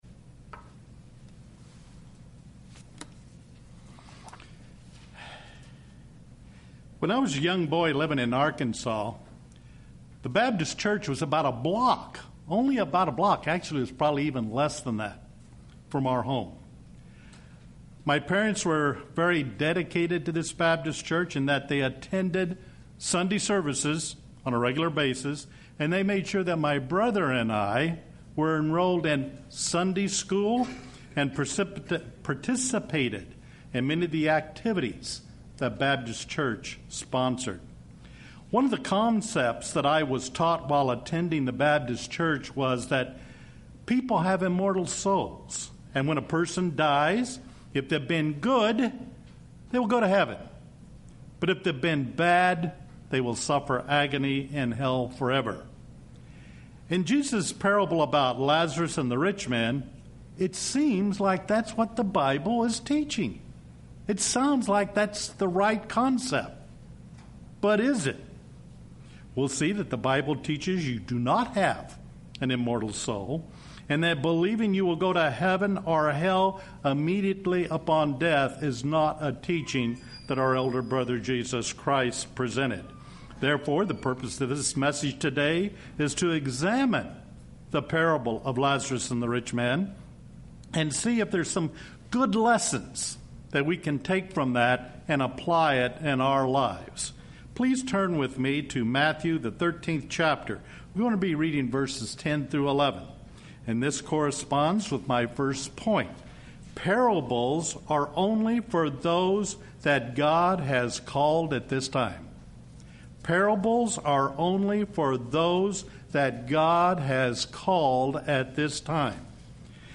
Given in Redlands, CA
UCG Sermon Studying the bible?